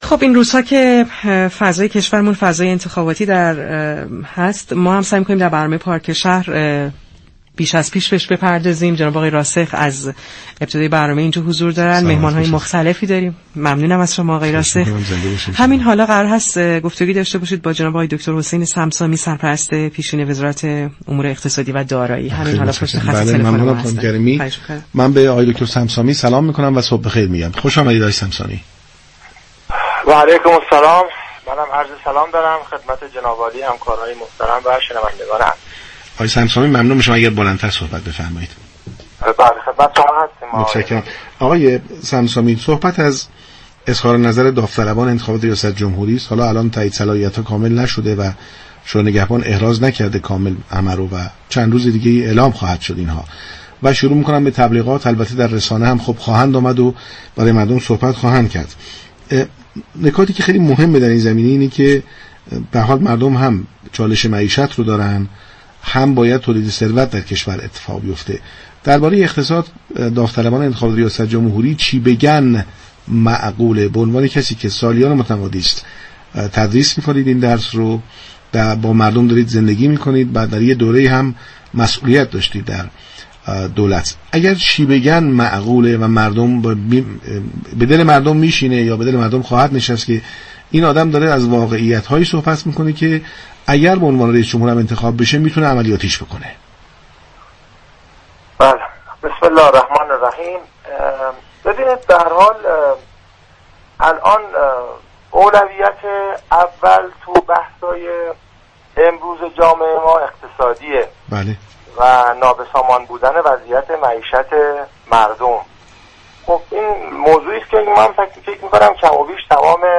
به گزارش پایگاه اطلاع رسانی رادیو تهران، حسین صمصامی سرپرست پیشین وزارت امور اقتصاد و دارایی در گفتگو با برنامه پارك شهر درباره اینكه اظهارات تاثیرگذار نامزدهای ریاست جمهوری در حوزه اقتصاد باید چه محتوایی داشته باشد تا به دل مردم بنشیند و در عین حال قابلیت عملیاتی شدن هم داشته باشد؛ گفت: اولویت اول امروز جامعه ما اقتصادی و معیشتی است و تمامی نامزدها روی این موضوع صحبت زیادی خواهند داشت اما اینكه برنامه ها، آسیب شناسی و راهكارهای ارائه شده توسط نامزدها و تیمی كه در آینده با آنها كارخواهد كرد بسیار مهم است.